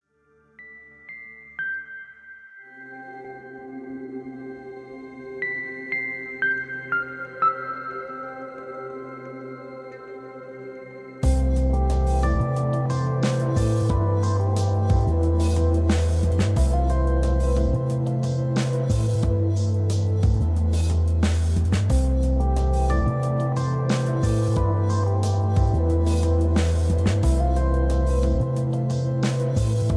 royalty free background music atmospheric moody